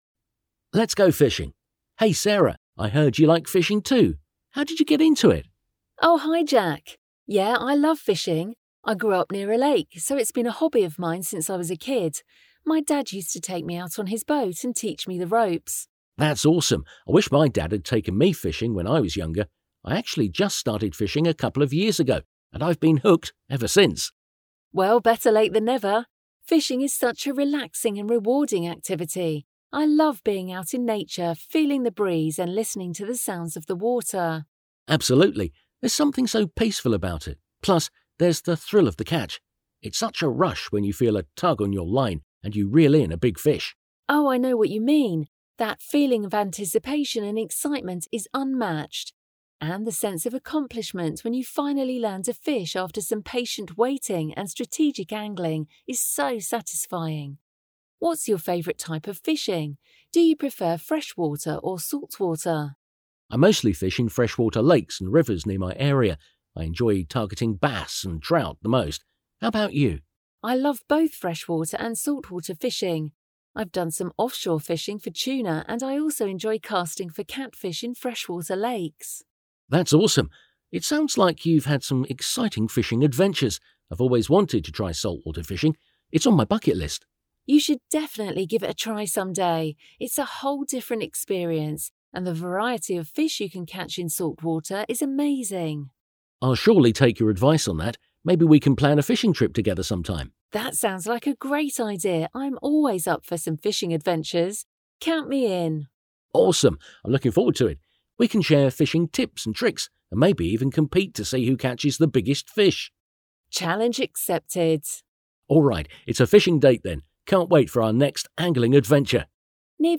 A 2023. májusi szám 78. oldalán található párbeszédet hallgathatod meg itt.
5-Lets-go-fishing-Dialogue.mp3